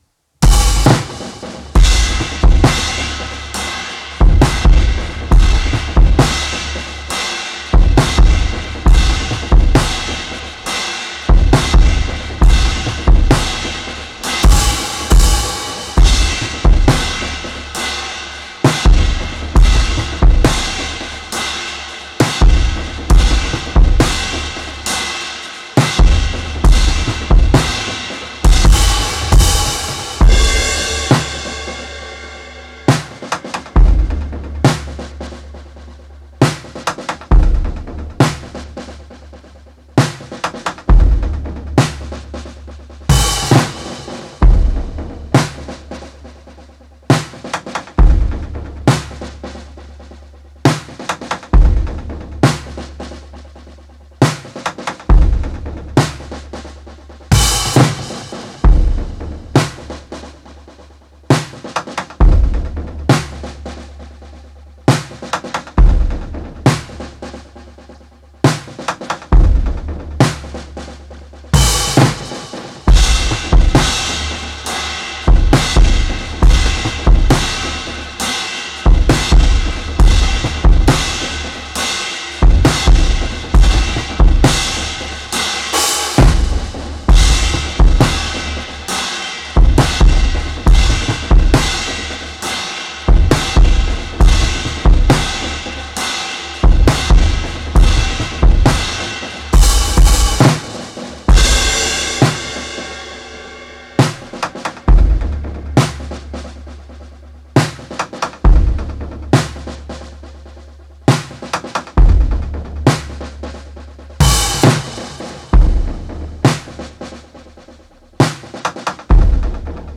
Genre:Weird, Experimental
Tempo:135 BPM (4/4)
Kit:Rogers 1983 XP8 24"
Mics:12 channels